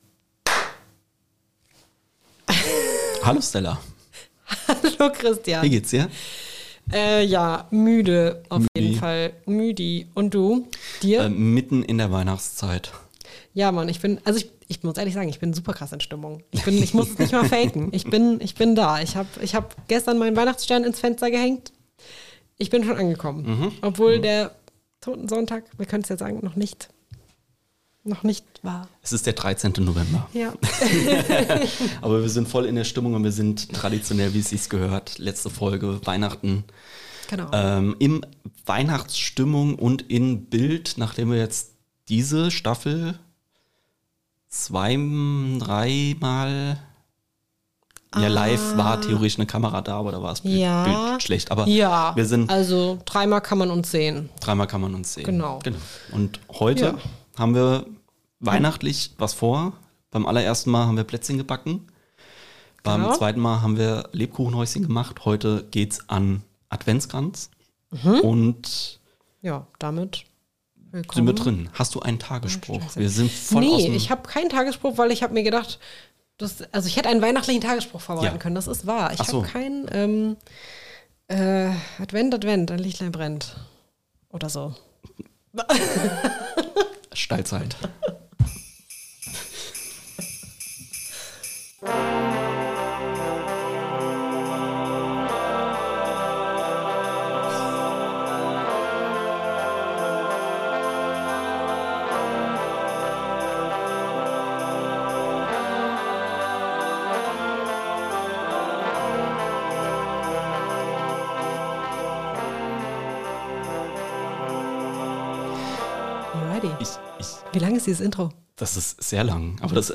Beschreibung vor 3 Monaten Wir laden euch ein zur weihnachtlichen Bastelstunde und blicken pünktlich zum Fest ganz feierlich zurück auf die zweite Staffelhälfte. Während der Glühäppler auf dem Herd vor sich hin simmert und auf dem Aufnahmetisch ein ganzes Arsenal an winterlicher Botanik bereit steht, schlüpfen die Hosts unter anderem in die Rollen ihrer vergangenen Gäst:innen. Kurzzeitig werden wir zu Theaterleiterinnen, fachsimpeln ganz agrarwirtschaftlich über Rosenkohl und reflektieren unser nachhaltig verändertes Verhalten im Straßenverkehr.